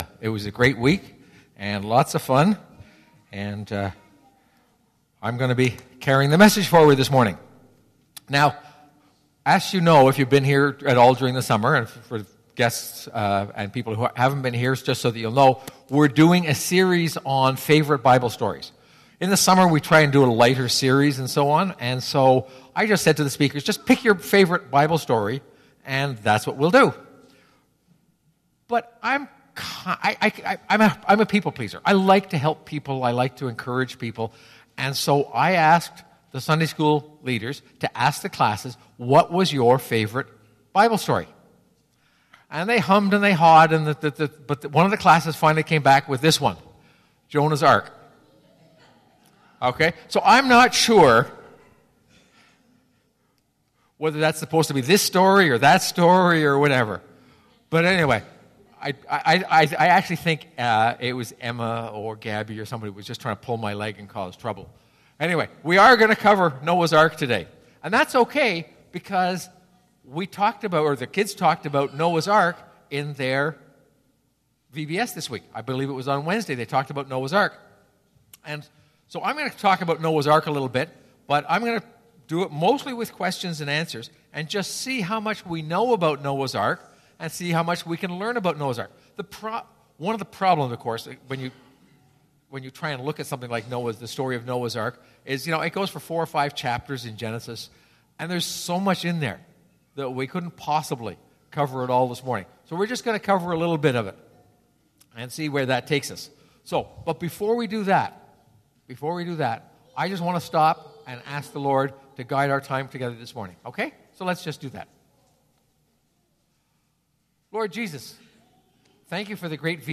PG Sermons August 19